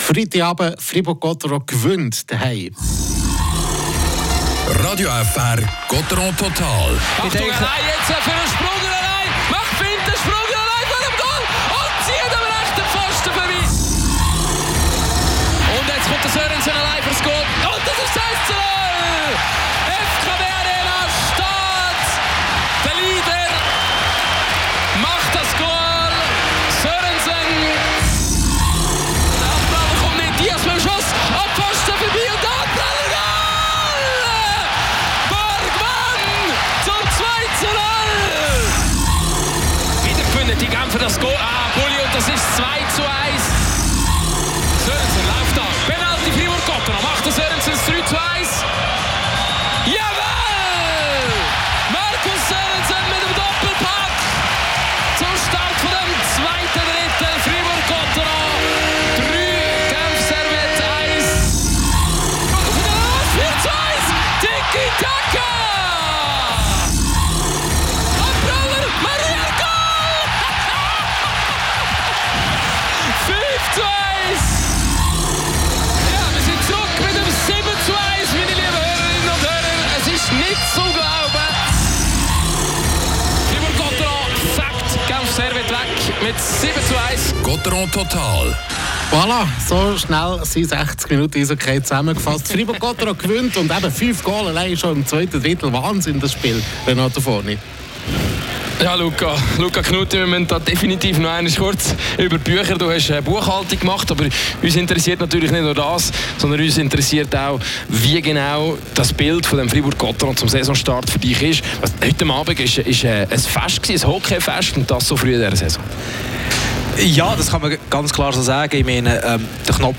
Interview mit dem Spieler Julien Sprunger.